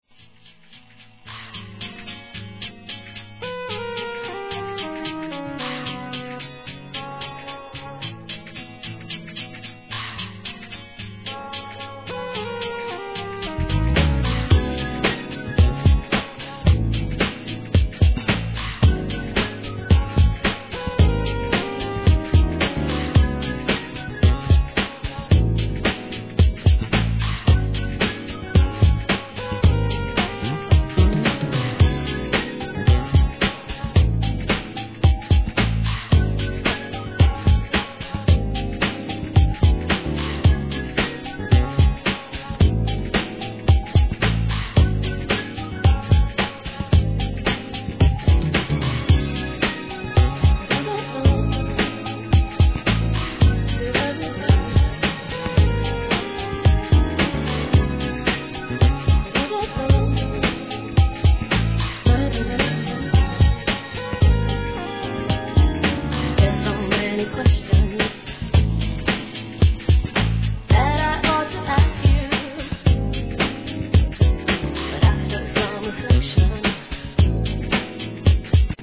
HIP HOP/R&B
いかにもUKらしいお洒落でアーバンなR&B!!